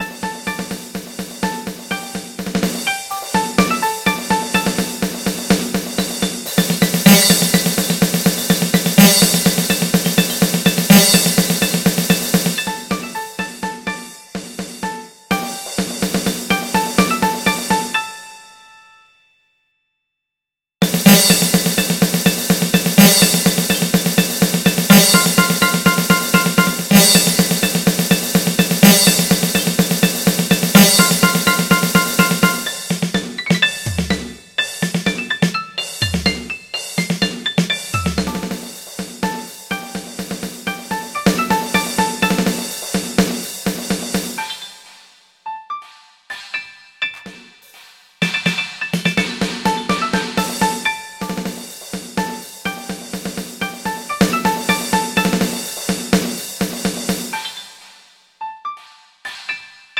Sour: high pitch, short duration, high dissonance